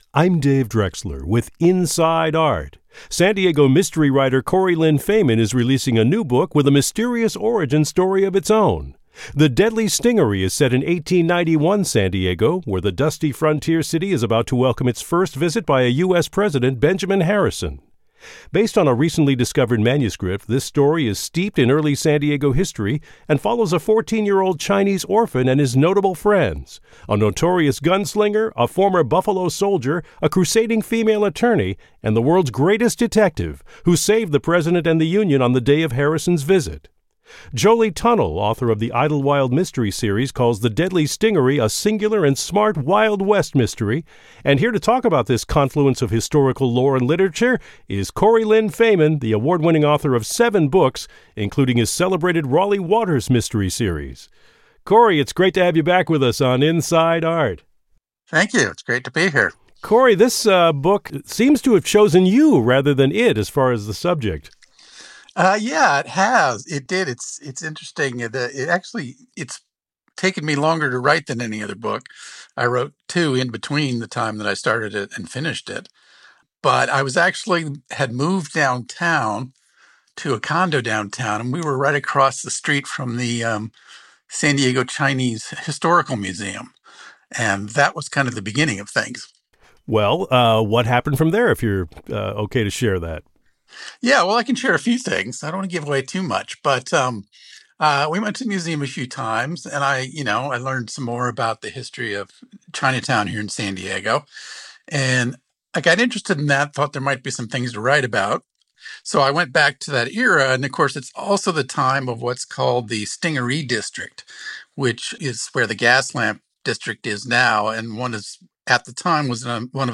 Radio Interview – The Deadly Stingaree